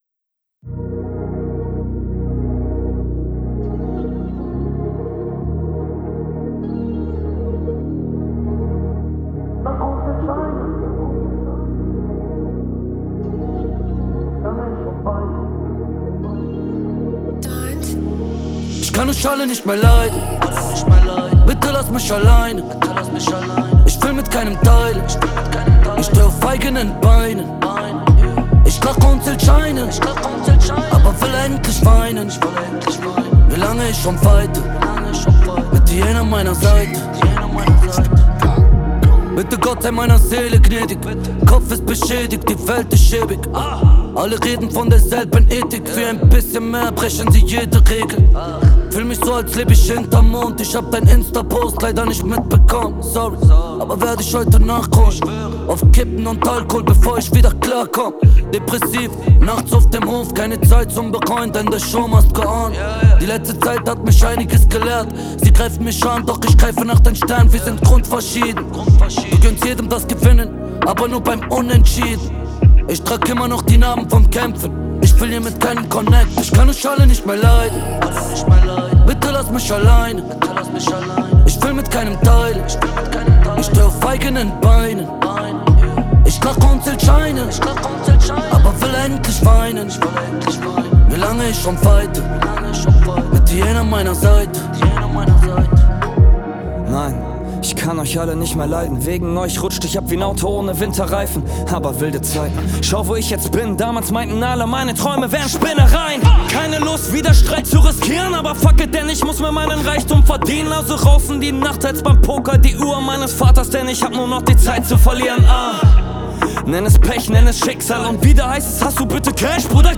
Ich meine ich komme damit super klar und das ist auch mittlerweile standart und definitiv kein Grund wenn ein Mix nicht funktioniert Hier mal ein aktueller song, gemixt in meinem Gäste Zimmer auf einem fertigen Beat mit den ksd c8 Anhang anzeigen 135441